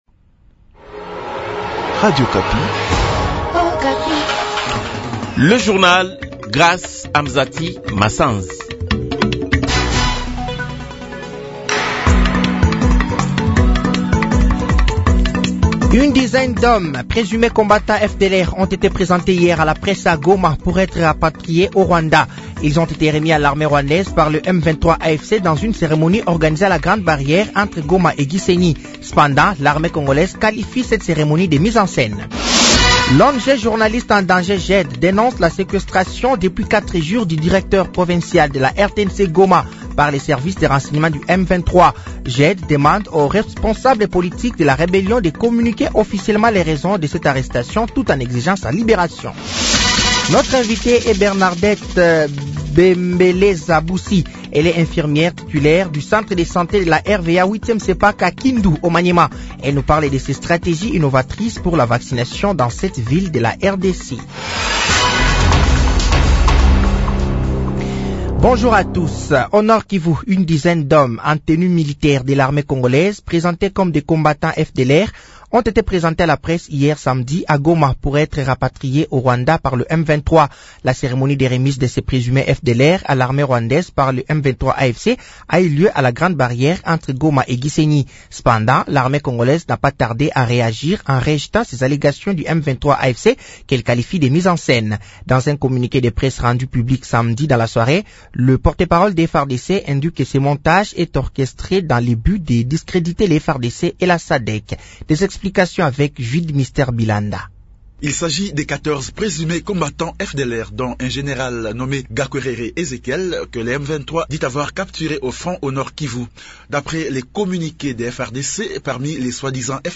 Journal de 15h
Journal français de 15h de ce dimanche 02 mars 2025